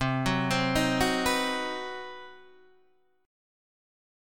C Minor Major 7th Flat 5th